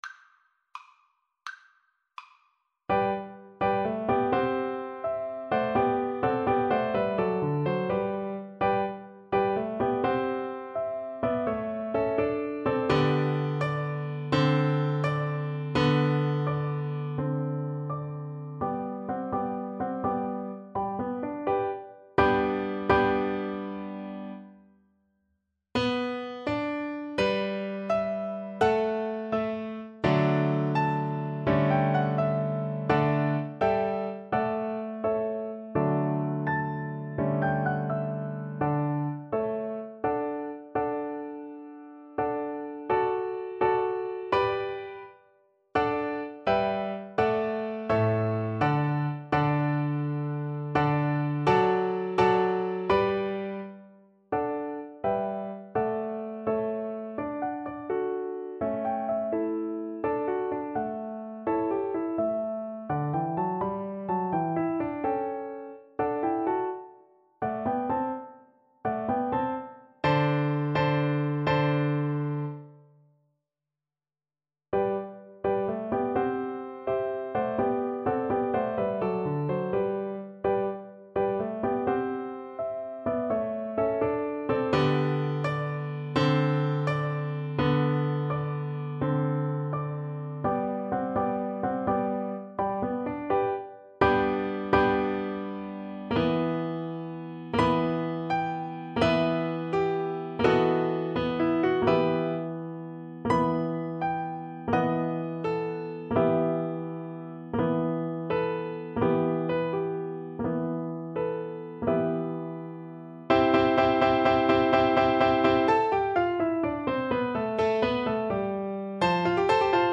G major (Sounding Pitch) (View more G major Music for Violin )
Grazioso .=84
6/8 (View more 6/8 Music)
Classical (View more Classical Violin Music)